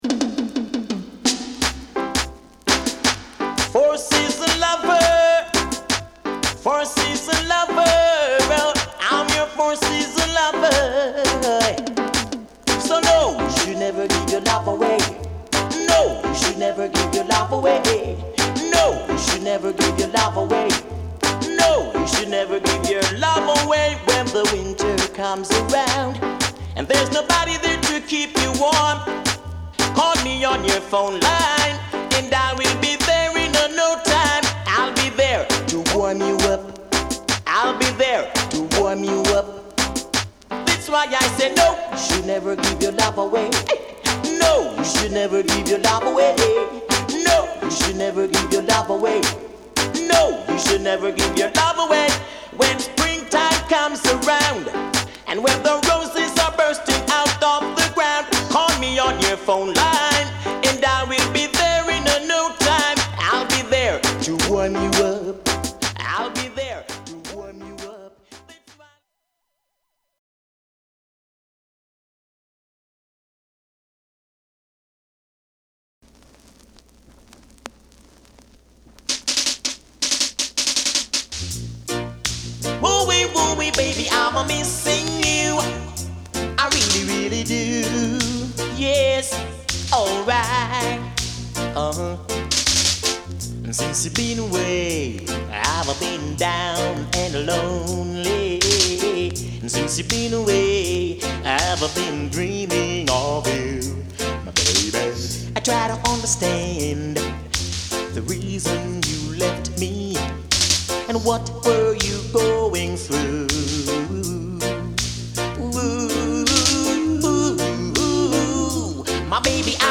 プレス・ノイズ有り（JA盤、Reggaeのプロダクション特性とご理解お願い致します）。